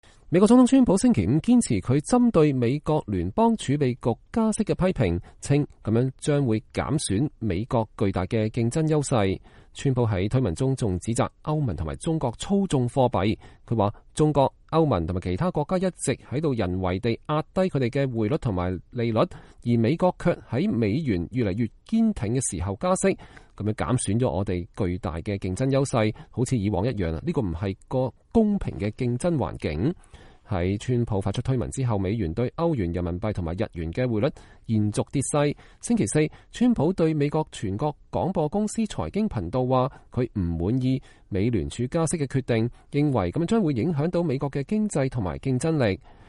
美國總統川普在白宮簽署成立美國工人全國委員會的行政令前的儀式上講話。（2018年7月19日）